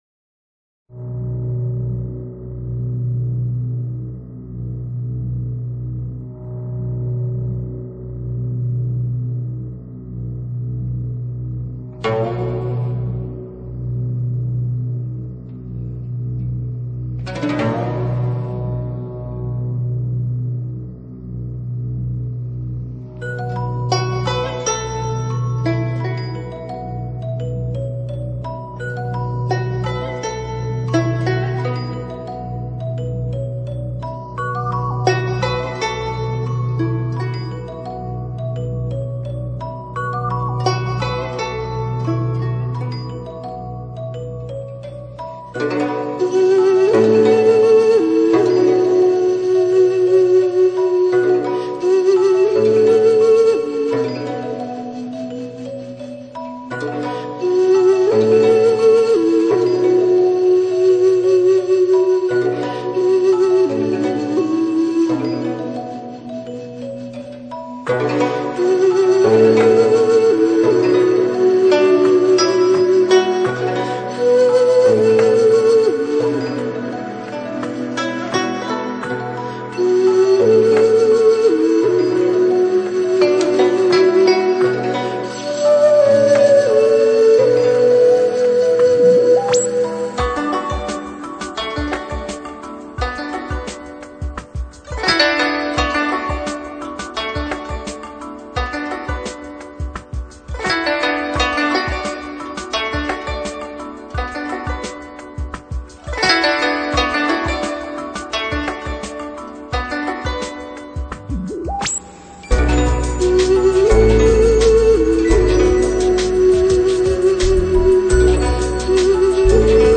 听一曲埙乐，品味远古的遗韵..........